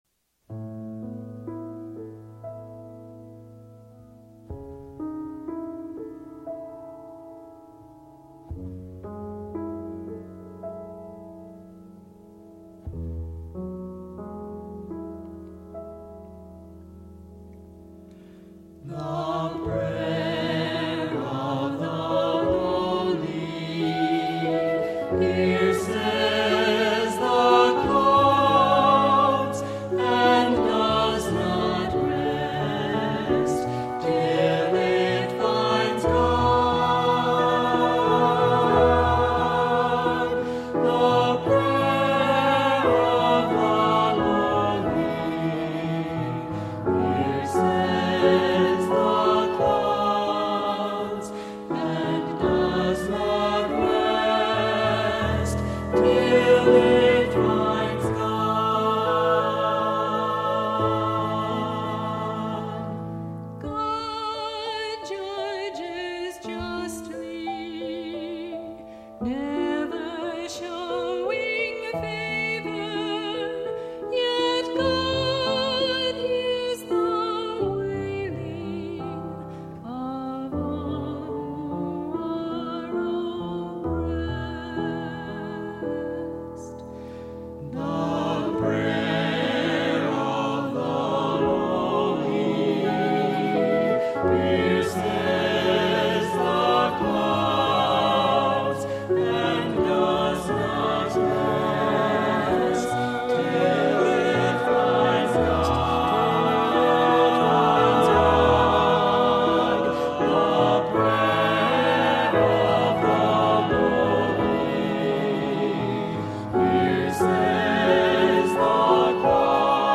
Voicing: Cantor